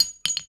weapon_ammo_drop_02.wav